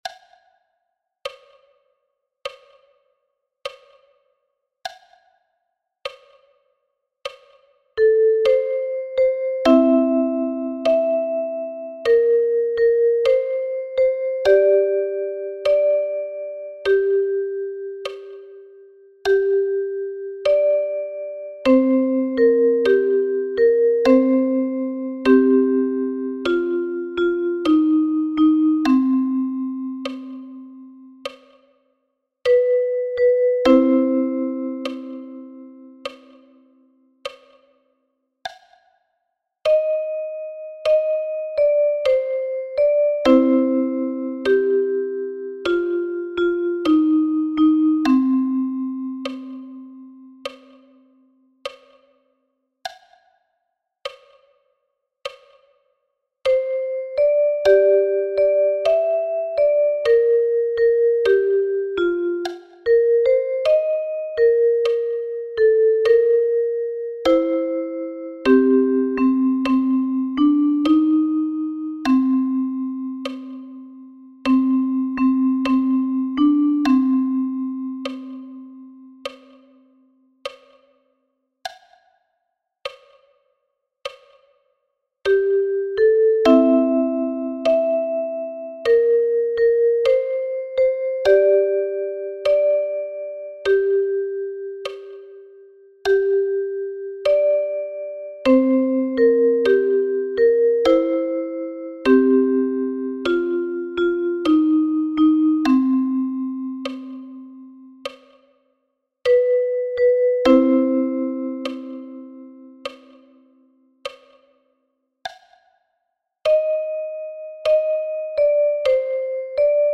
für die Ukulele